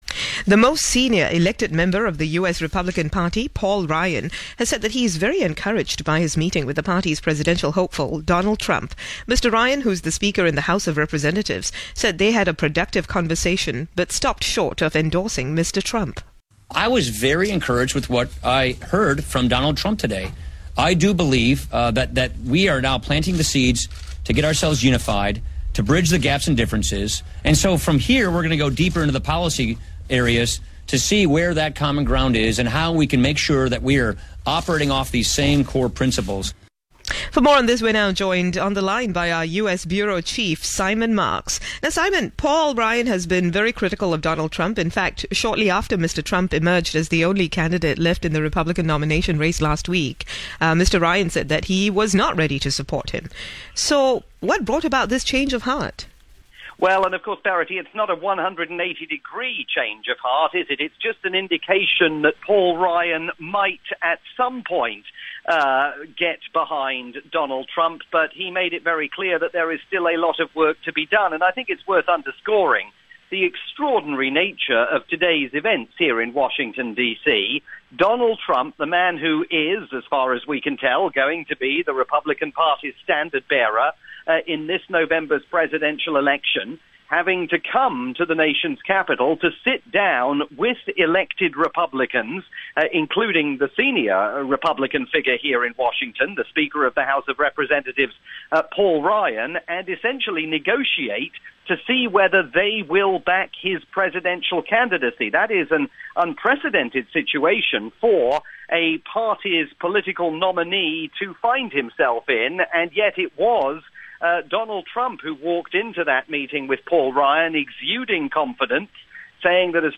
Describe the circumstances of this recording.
This live report aired on Singapore's 938 Live.